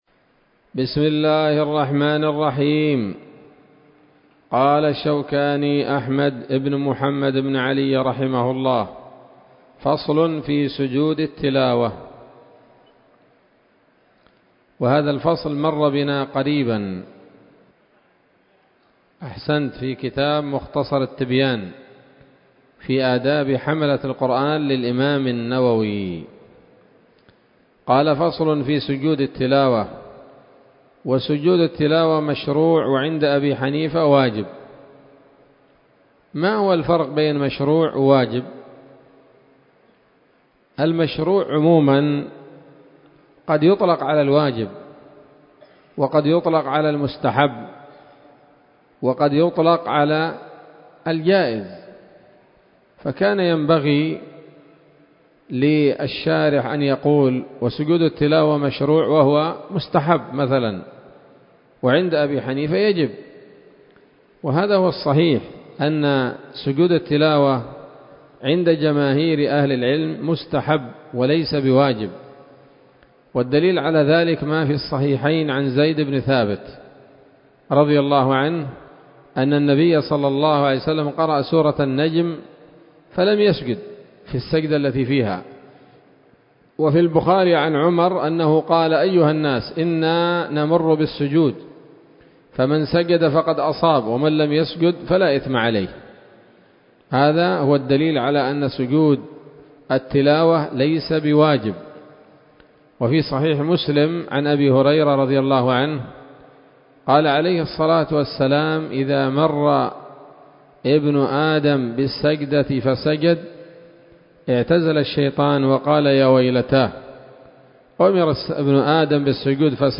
الدرس الخامس والثلاثون من كتاب الصلاة من السموط الذهبية الحاوية للدرر البهية